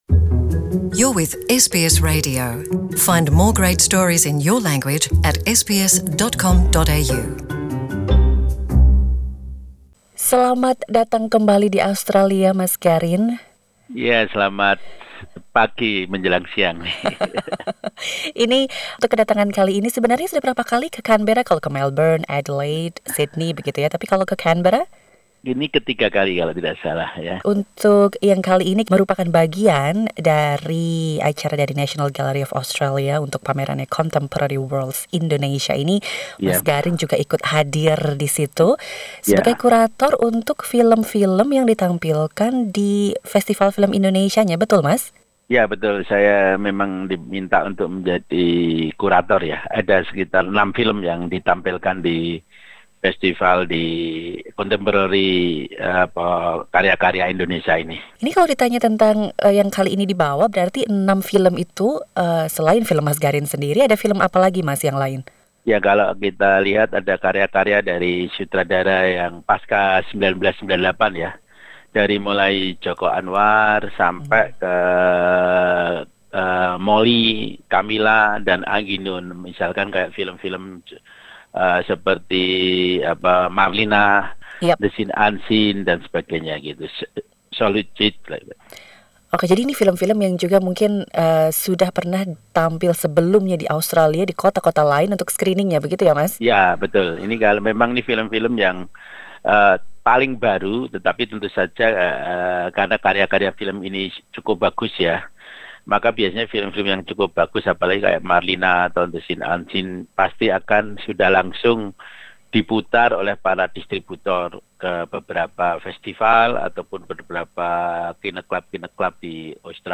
Sutradara film Indonesia yang terkenal, Garin Nugroho, mengunjungi Galeri Nasional Australia untuk sebuah pameran dan berbicara dengan SBS Indonesian tentang keterlibatannya dalam pameran tersebut.